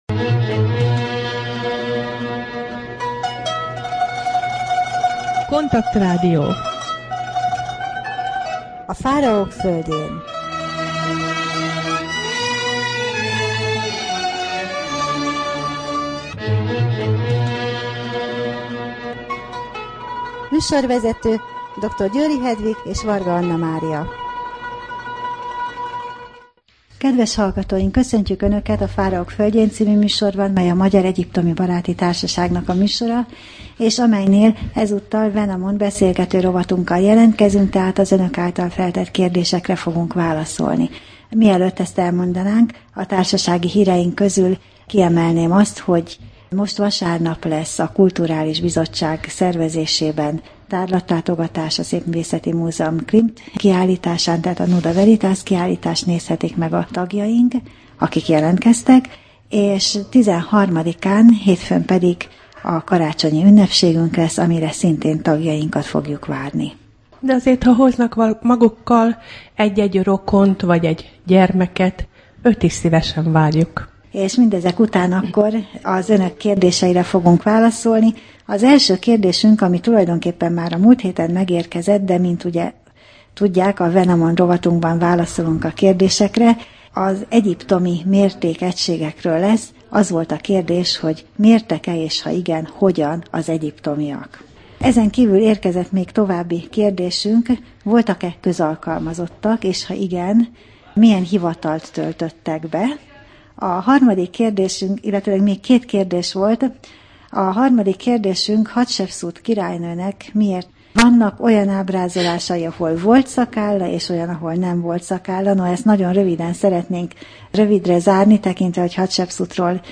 Wenamon beszélgető rovat: Mérések és mértékegységek | Magyar Egyiptomi Baráti Társaság